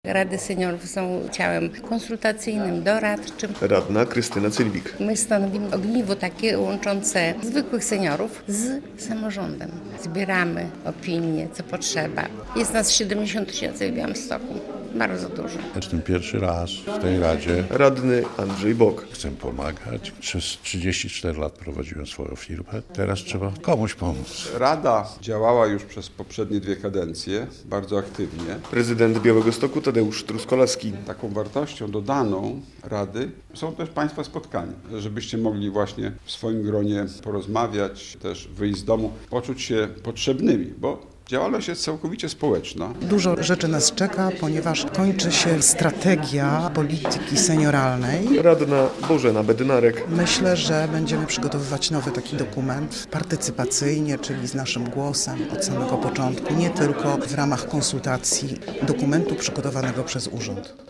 Białostocka Rada Seniorów - relacja
Uroczyste nominacje z rąk prezydenta Truskolaskiego radni-seniorzy odebrali w Pałacyku Gościnnym.